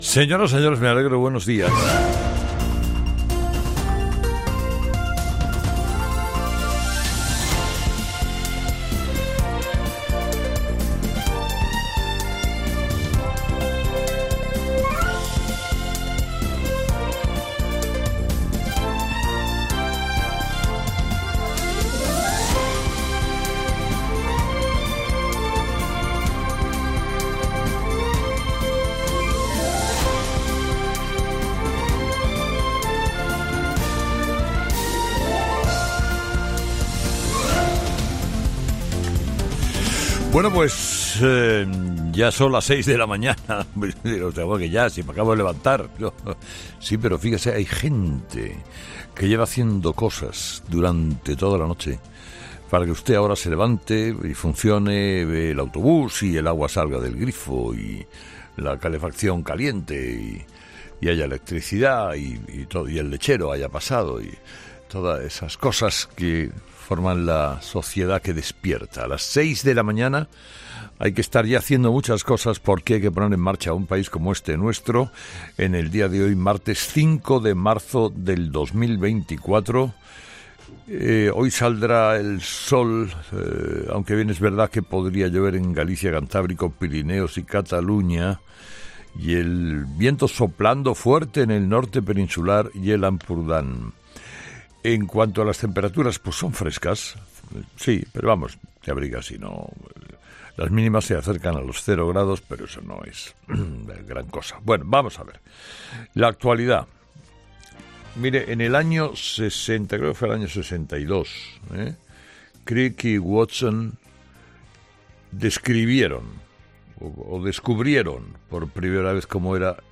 Carlos Herrera, director y presentador de 'Herrera en COPE', comienza el programa de este martes analizando las principales claves de la jornada que pasan, entre otras cosas, por Armengol señala a Transportes y Ábalos a Santos Cerdán.